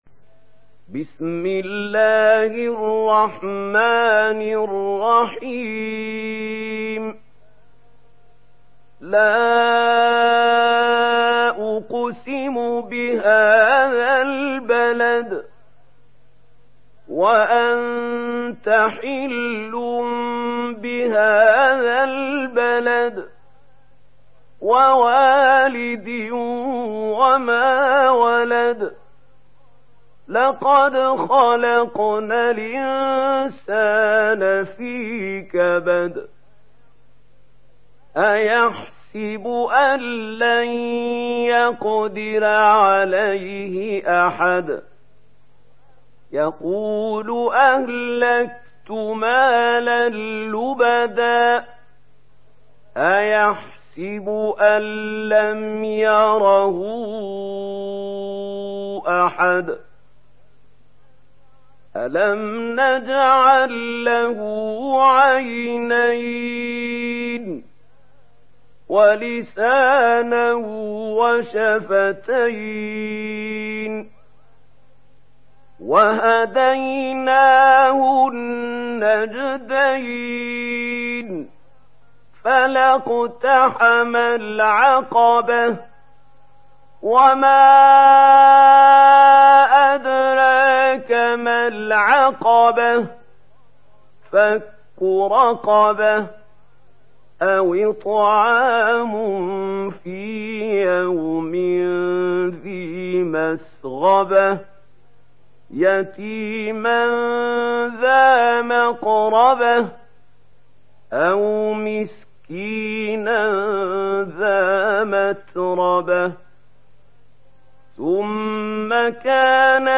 Sourate Al Balad mp3 Télécharger Mahmoud Khalil Al Hussary (Riwayat Warch)
Sourate Al Balad Télécharger mp3 Mahmoud Khalil Al Hussary Riwayat Warch an Nafi, Téléchargez le Coran et écoutez les liens directs complets mp3